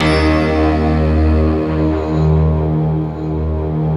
SI1 PLUCK03R.wav